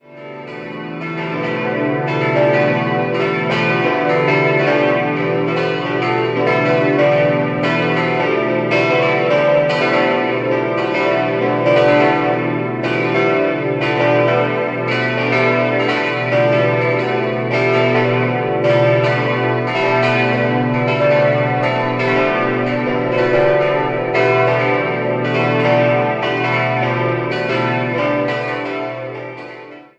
5-stimmiges Geläute: h°-d'-e'-fis'-a'
Füssen Acht Seligkeiten.mp3